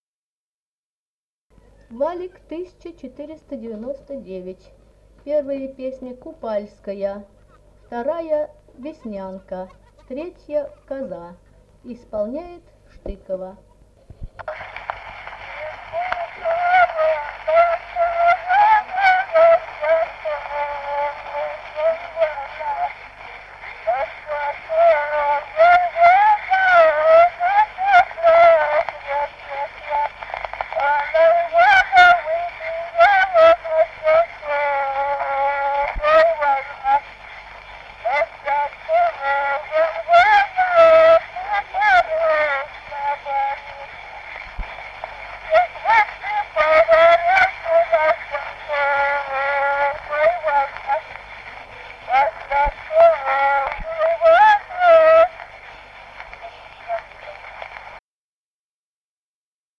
Народные песни Стародубского района «Где купала ночевала», купальская.
1951 г., с. Азаровка.